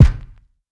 KICK 5.wav